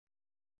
♪ ṛanjike